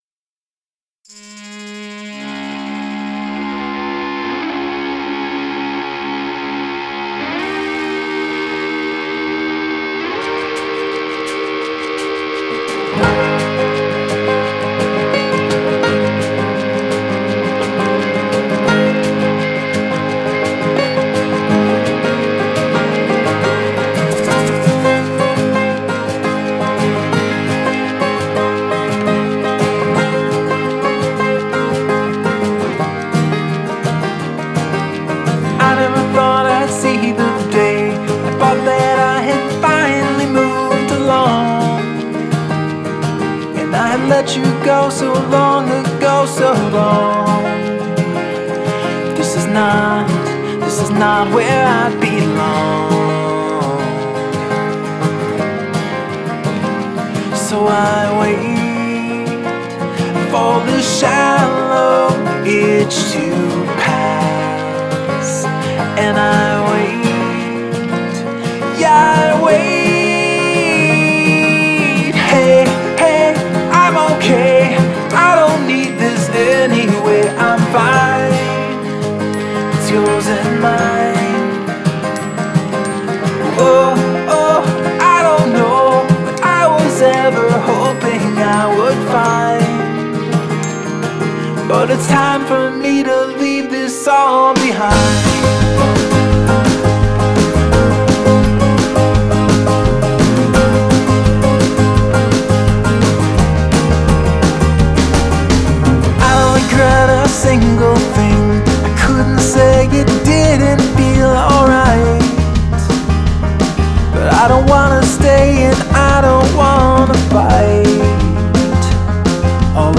• Genre: Pop